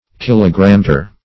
Search Result for " kilogrammetre" : The Collaborative International Dictionary of English v.0.48: Kilogrammeter \Kil"o*gram*me`ter\, Kilogrammetre \Kil"o*gram*me`tre\, n. (Mech.)
kilogrammetre.mp3